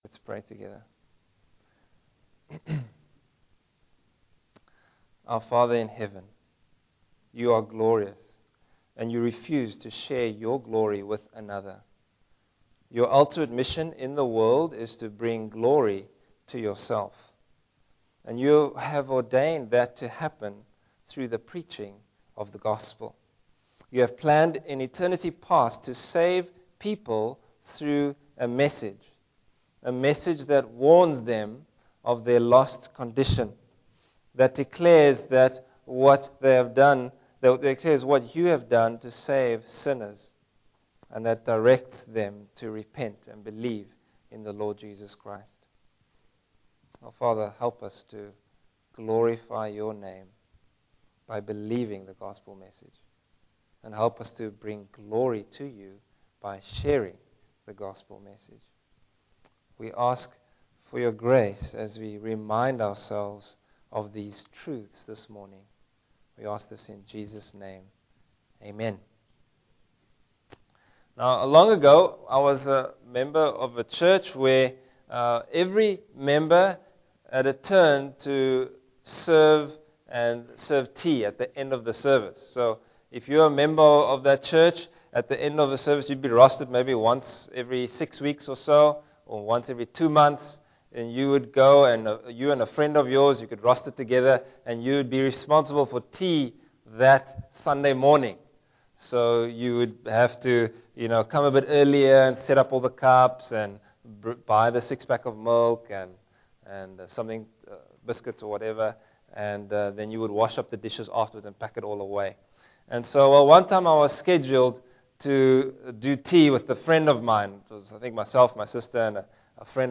Bible Text: Matthew 28:16-20 | Preacher